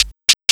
PERC LOOP3-R.wav